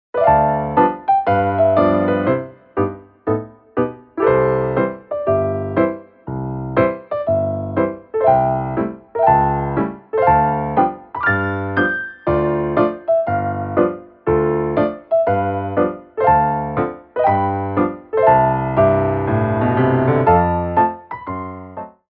MEDIUM TEMPO